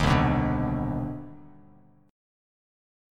CmM9 chord